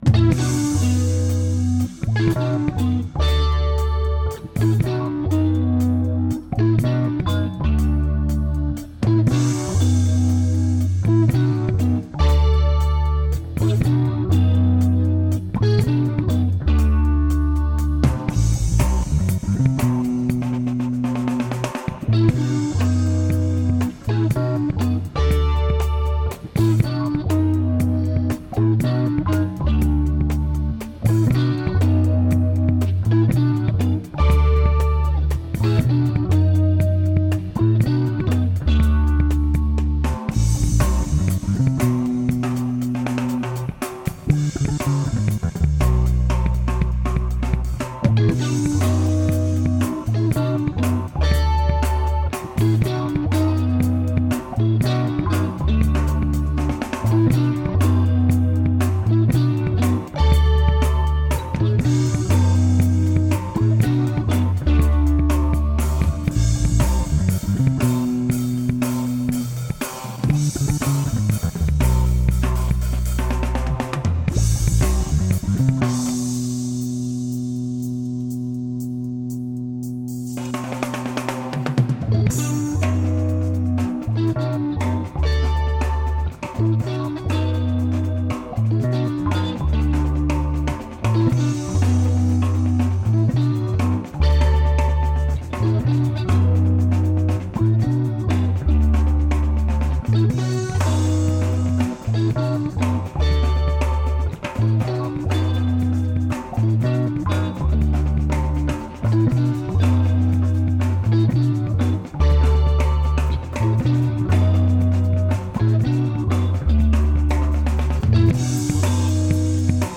with no lead guitar lines